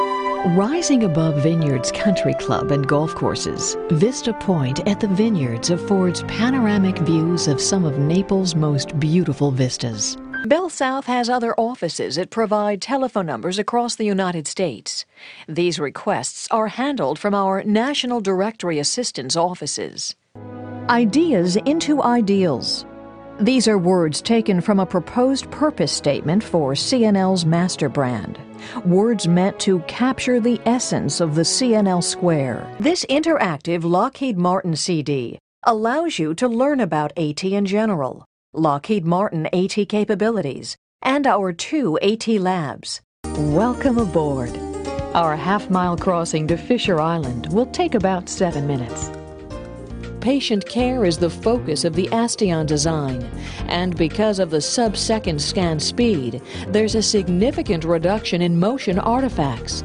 Mature Adult, Adult, Child, Young Adult Has Own Studio I delight in bringing words to life!
I have a clear, crisp, authoritative sound and complex verbiage is my forte.